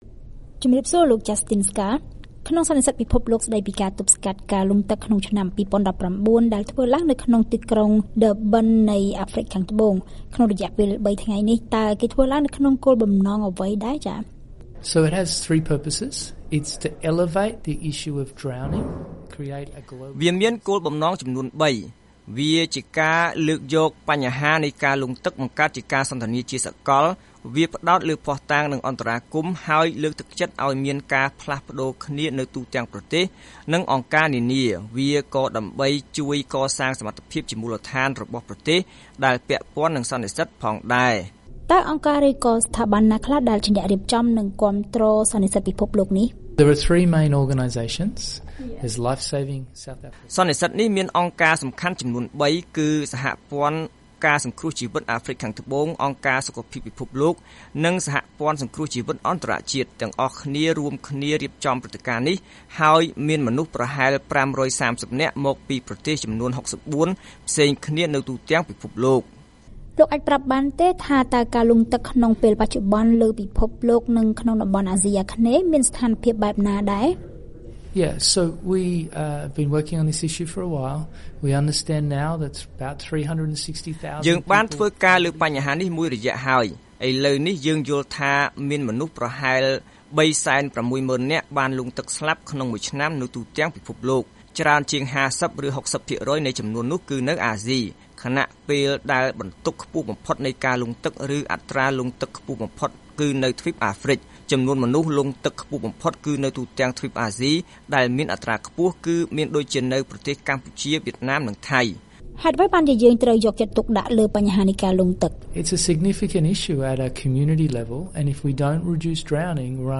បទសម្ភាសន៍ VOA៖ សន្និសីទពិភពលោកស្ដីពីការបង្ការការលង់ទឹករំពឹងថាការស្លាប់ដោយលង់ទឹកនឹងត្រូវបានកាត់បន្ថយ